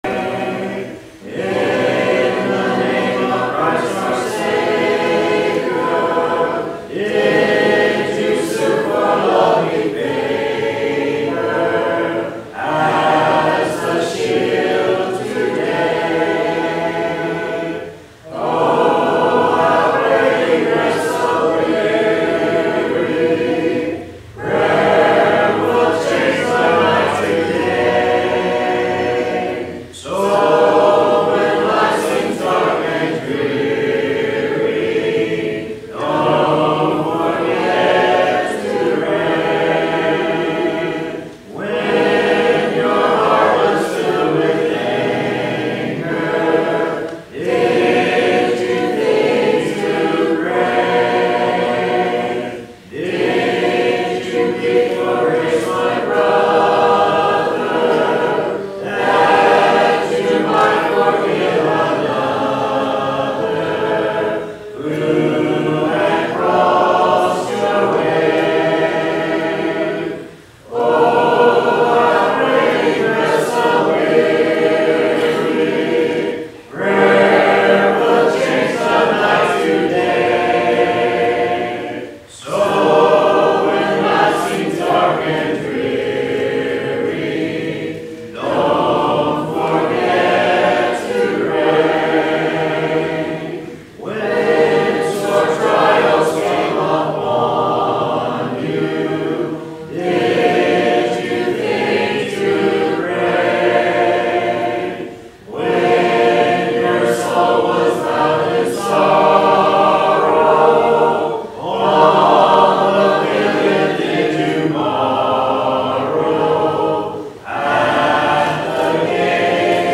Sun PM – 5th Sunday Night Sing – Prayer – 29 March 2026